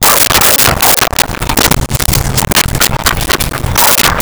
Dog Barking 02
Dog Barking 02.wav